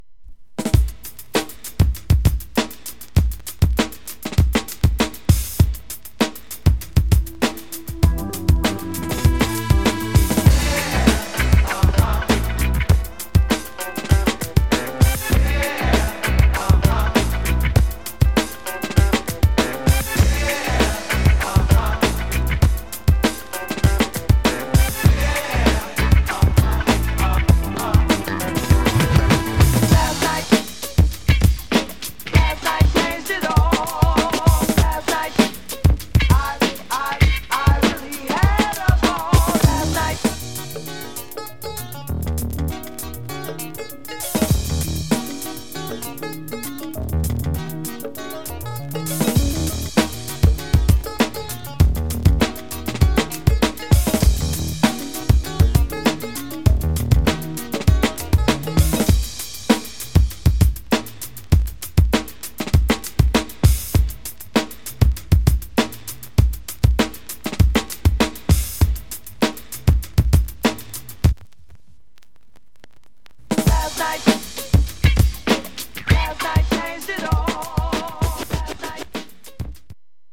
(Instrumental Version)